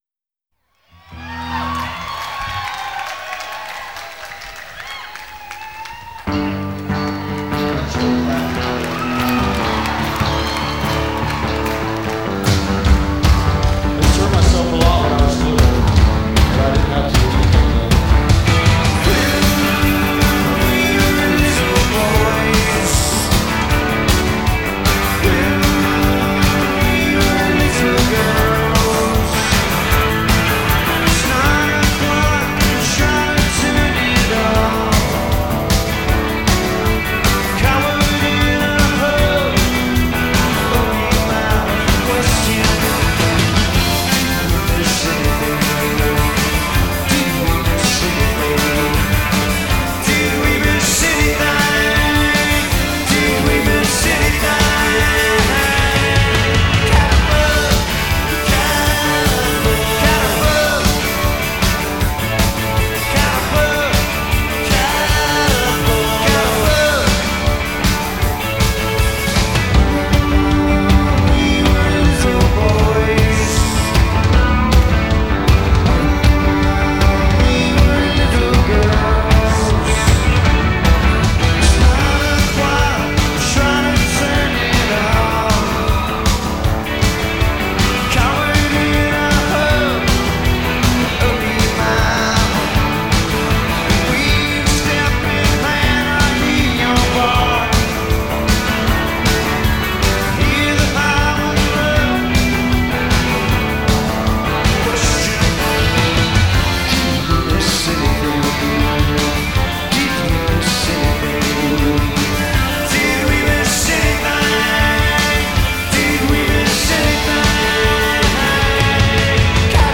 As I suggested above, it is indeed quite the rocker.